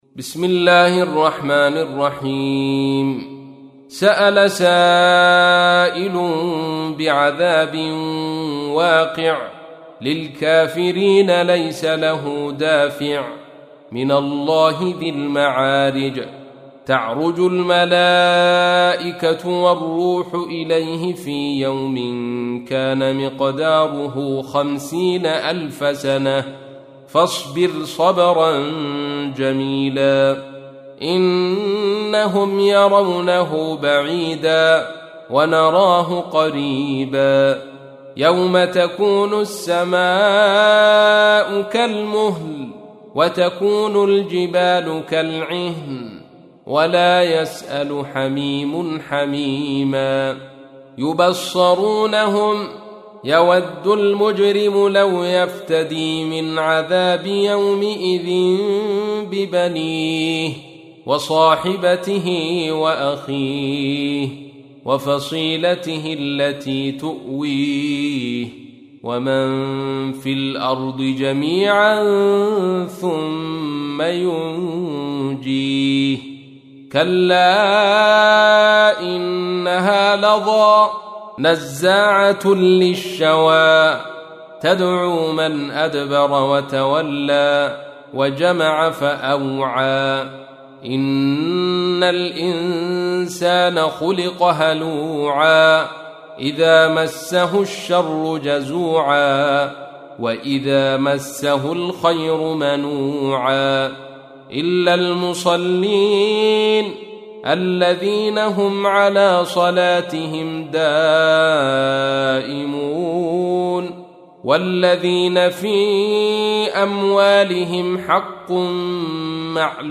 تحميل : 70. سورة المعارج / القارئ عبد الرشيد صوفي / القرآن الكريم / موقع يا حسين